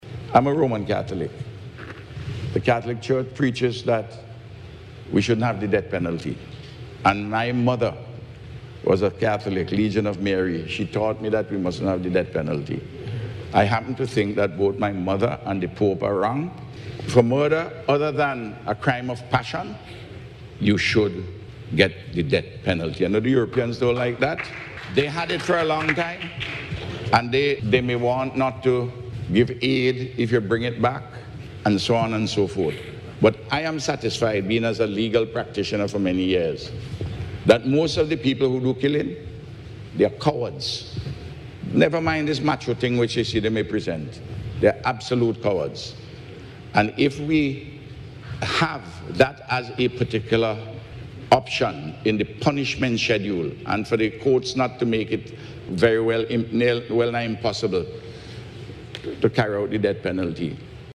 The Prime Minister raised the issue on Monday at the CARICOM Regional Symposium on Violence as a Public Health Issue, which wrapped up in Trinidad yesterday.